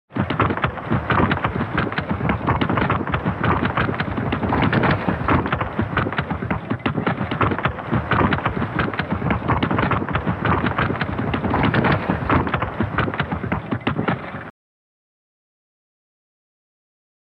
جلوه های صوتی
دانلود صدای بوفالو 2 از ساعد نیوز با لینک مستقیم و کیفیت بالا